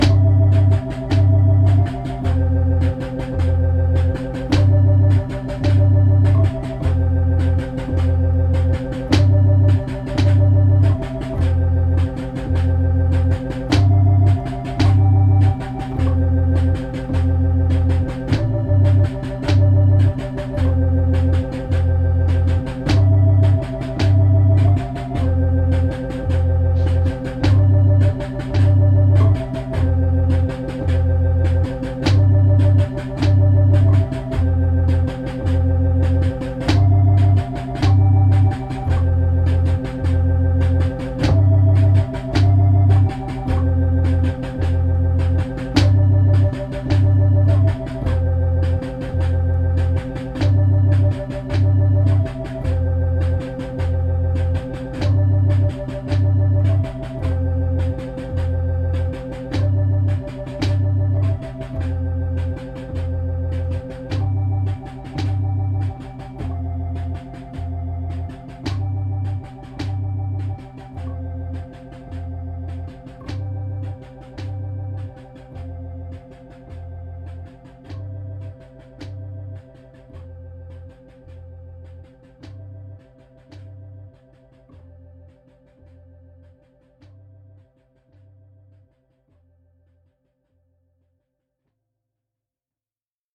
STE-023_orgeon_organ_day1.mp3